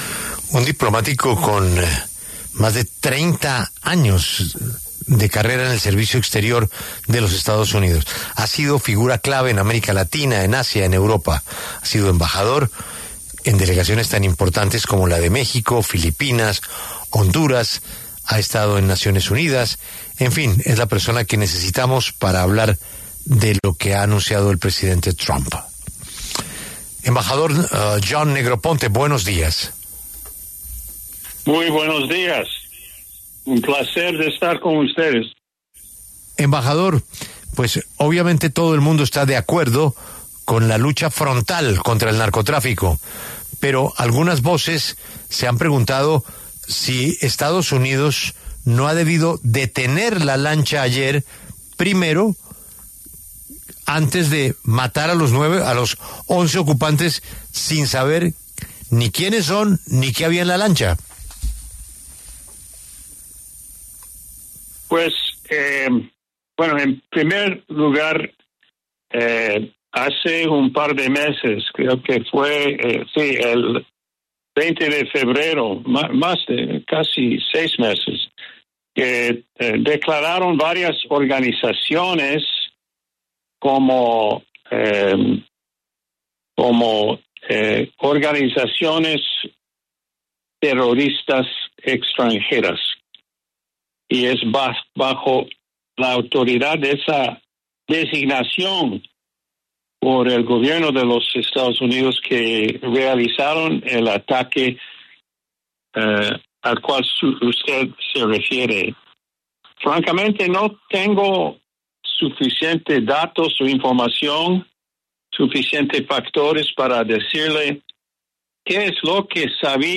El embajador John Negroponte, exdirector de Inteligencia Nacional de Estados Unidos, habló en La W sobre el ataque contra una lancha que presuntamente transportaba drogas.